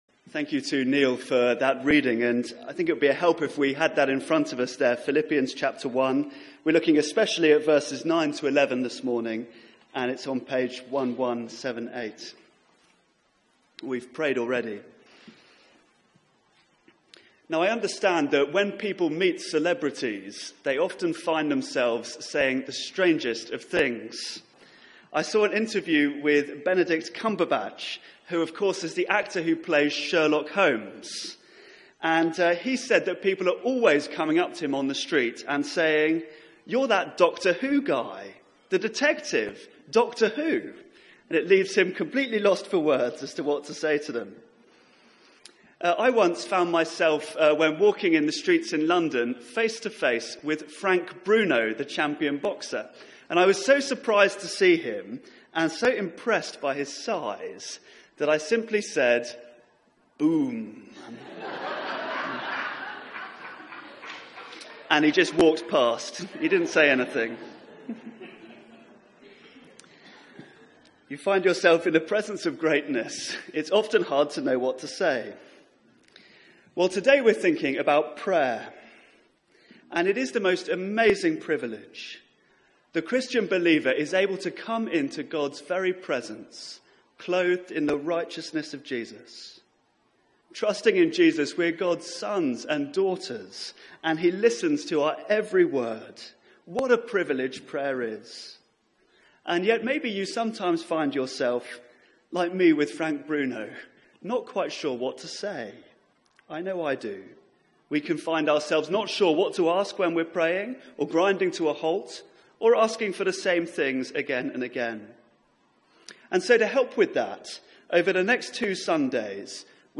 Media for 9:15am Service
Passage: Philippians 1:1-11 Series: Praying with Paul Theme: A Prayer for Growth Sermon